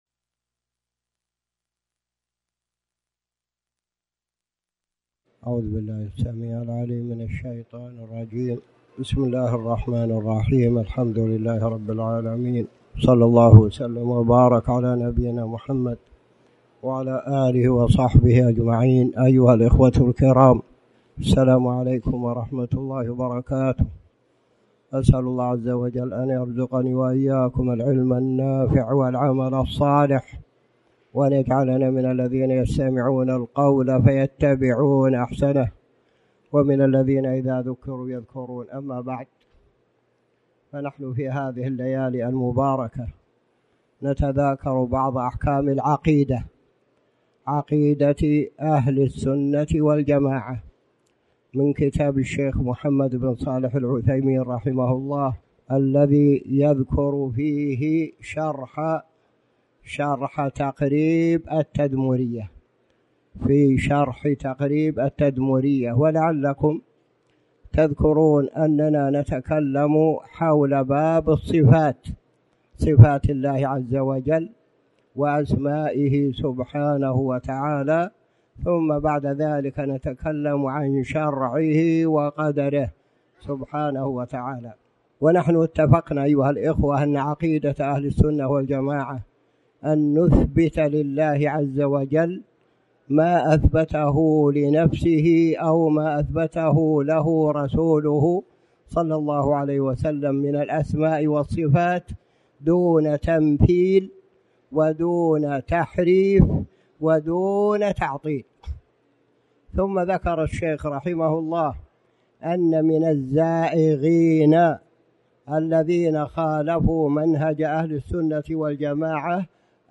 تاريخ النشر ٥ ذو القعدة ١٤٣٩ هـ المكان: المسجد الحرام الشيخ